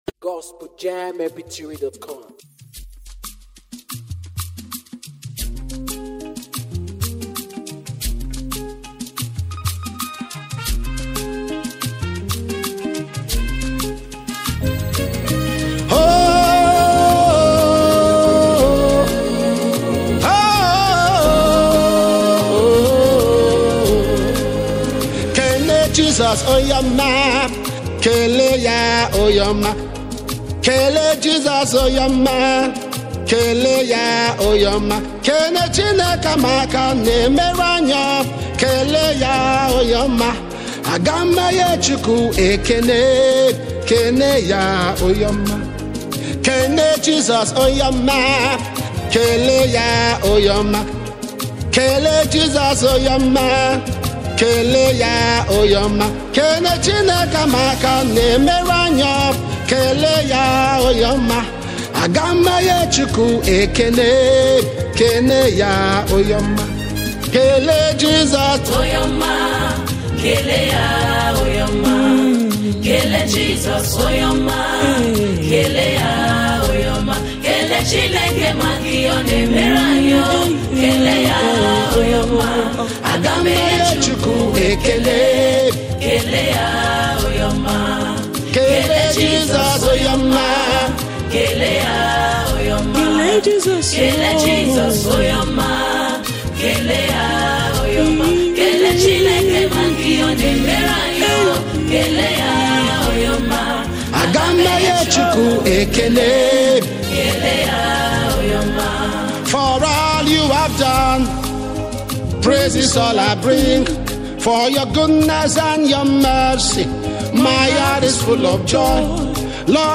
rich harmonies